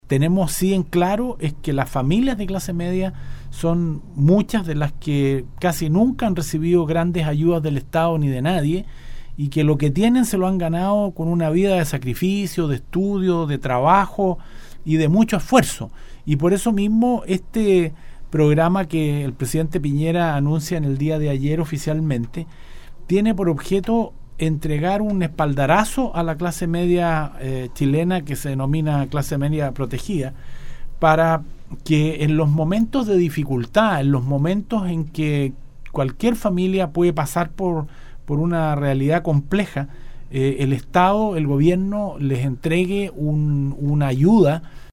El Ministro de Minería, Baldo Prokurica estuvo en los estudios de Nostálgica, donde pudo profundizar en la reciente presentación que efectuó el Presidente de la República, del programa llamado Clase Media Protegida.